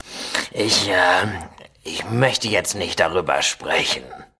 Fallout: Audiodialoge